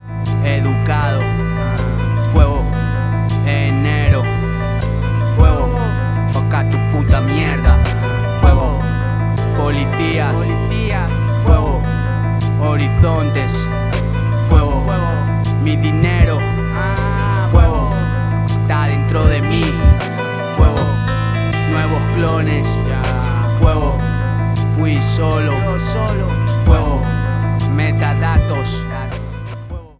Hip Hop/Dj Tools